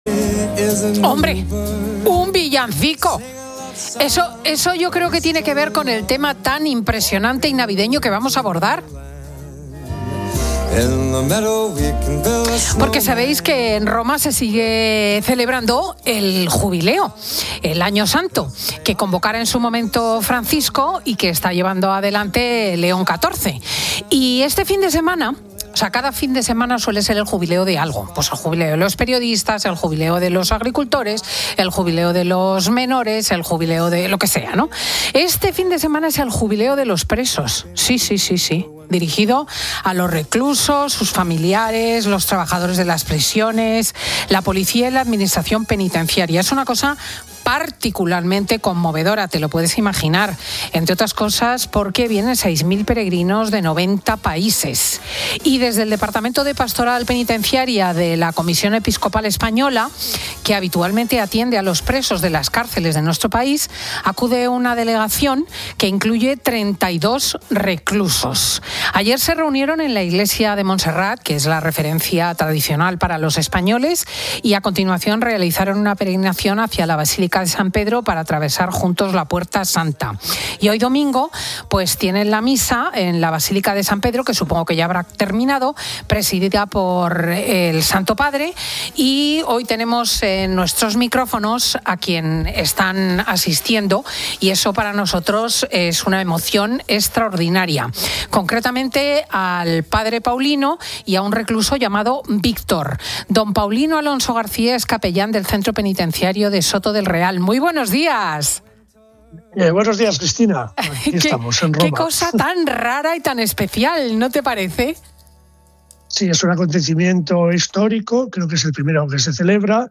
han compartido su testimonio en el programa 'Fin de Semana COPE' con Cristina López Schlichting.